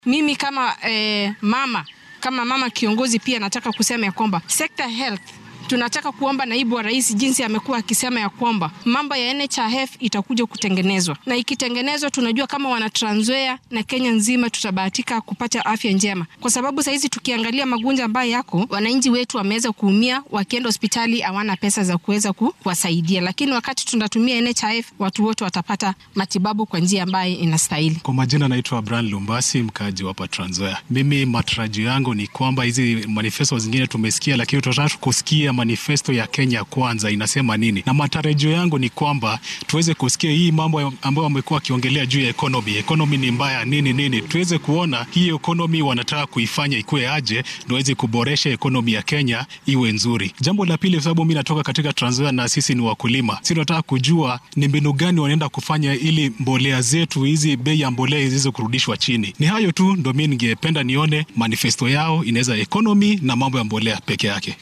Qaar ka mid ah shacabka ismaamulka Trans Nzoia ayaa ka ra’yi dhiibtay waxyaabaha ay rajeynayaan in isbeheysiga Kenya Kwanza uu maanta qorshihiisa ku soo bandhigo.